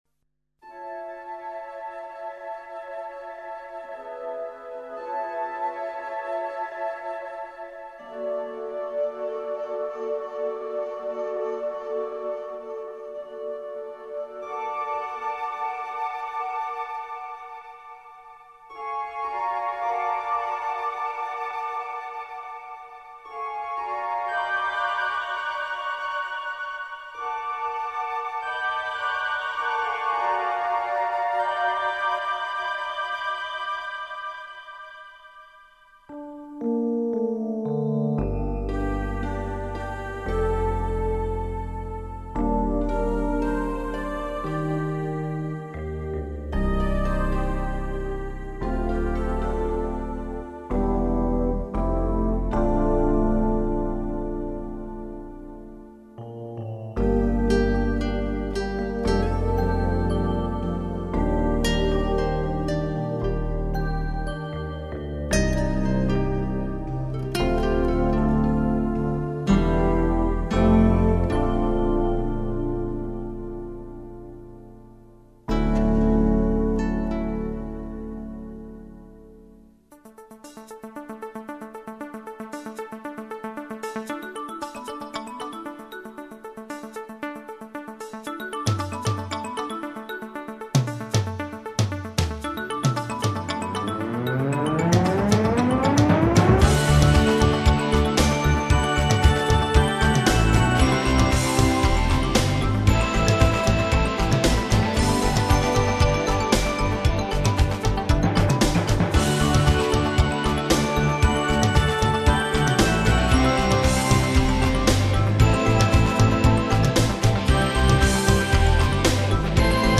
A few musical pieces composed by the artist
Mp3 file, 112 kbps, Mono. Instrumental piece contained in "Myths".